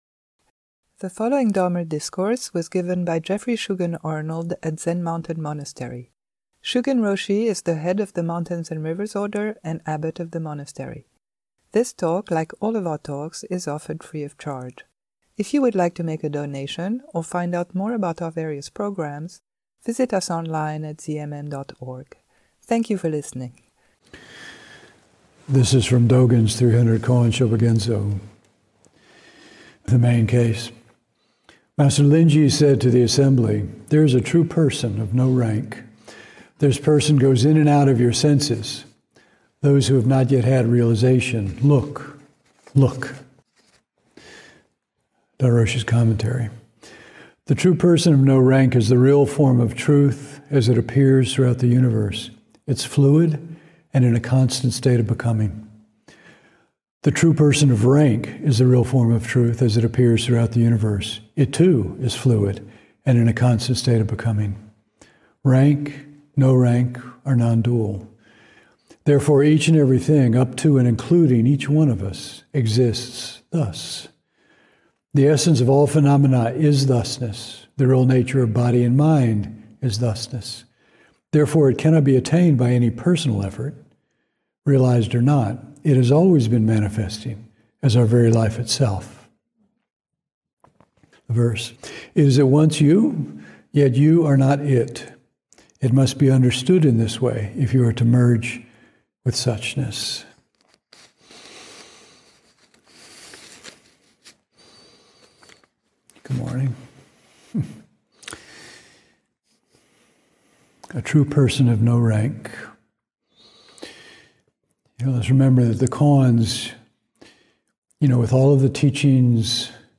Podcasts sobre Zen Mountain Monastery